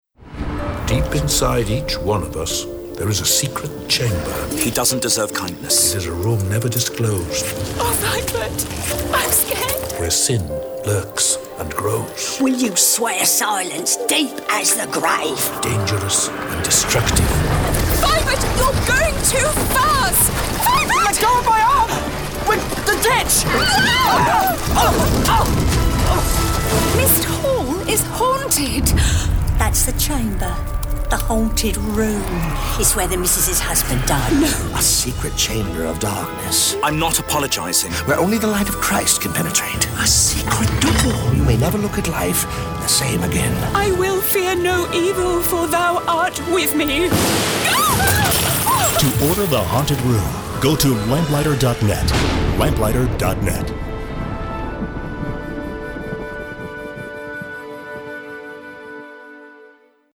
Dramatic Audio CD – Haunted Room